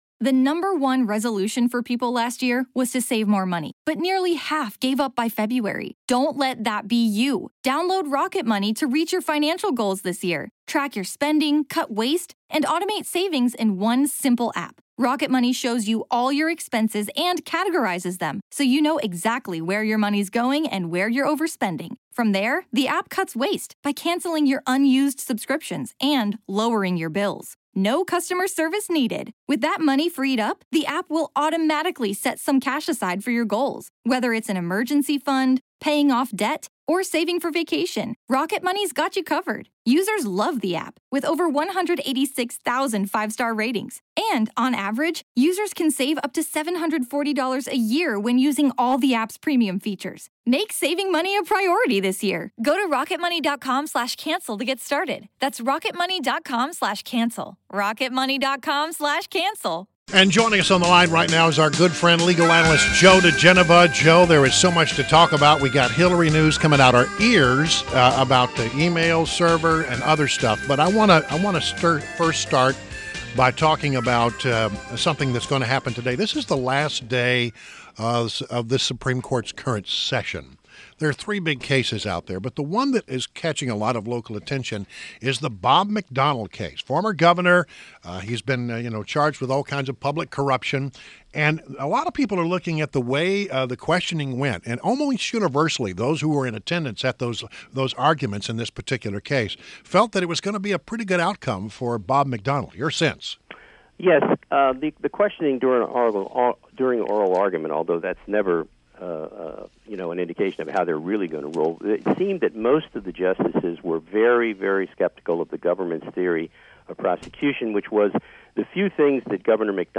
WMAL Interview - Joe Digenova - 06.27.16